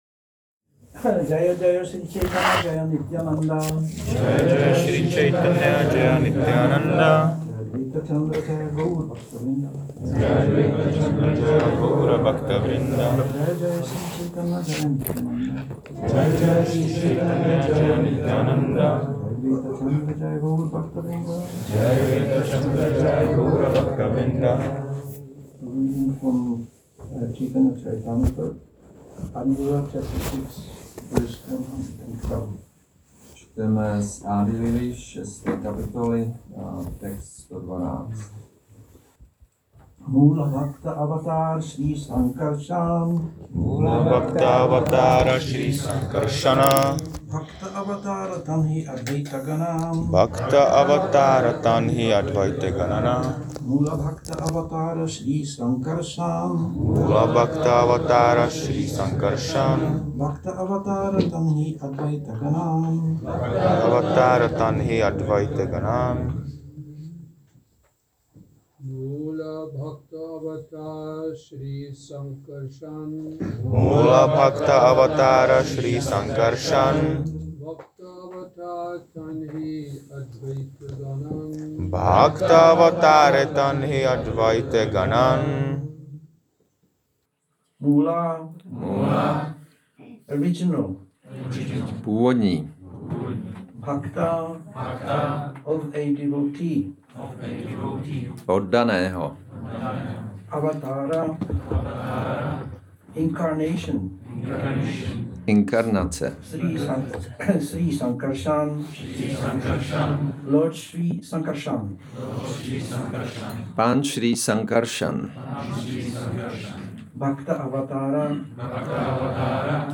Šrí Šrí Nitái Navadvípačandra mandir
Přednáška CC-ADI-6.112